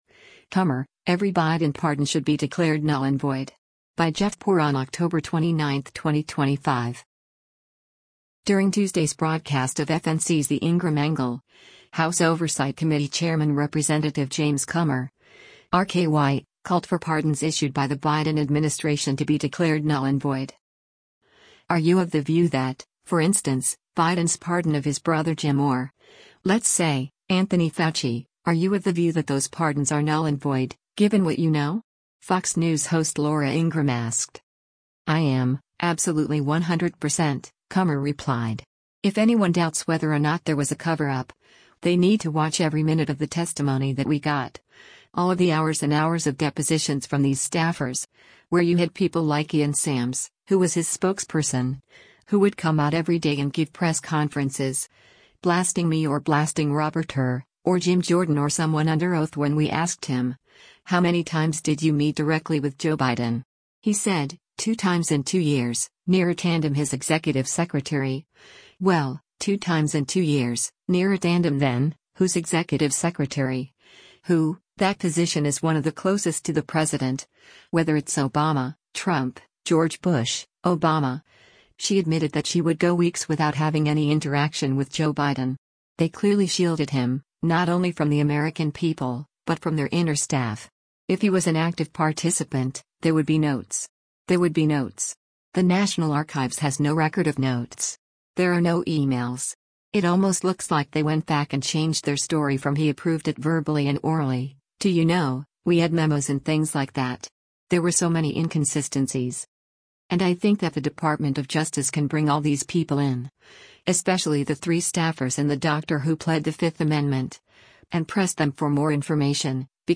During Tuesday’s broadcast of FNC’s “The Ingraham Angle,” House Oversight Committee chairman Rep. James Comer (R-KY) called for pardons issued by the Biden administration to be declared “null and void.”